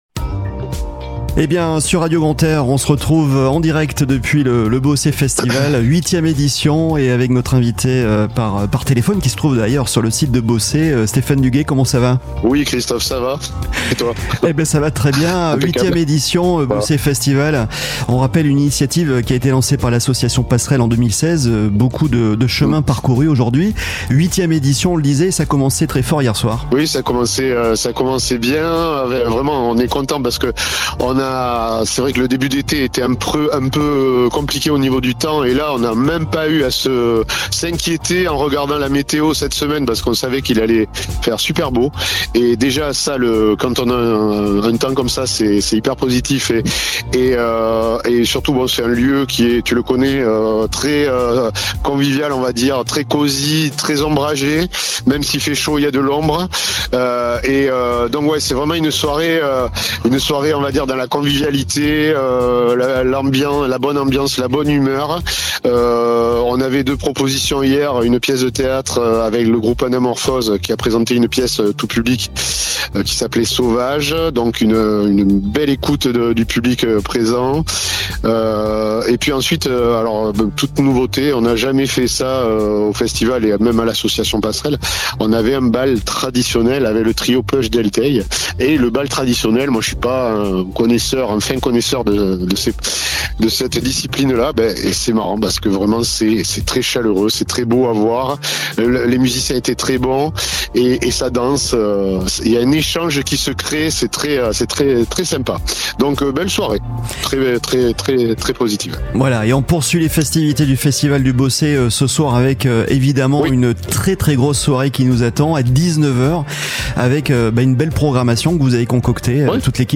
3. Les invités sur Radio Grand "R"